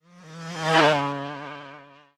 car8.ogg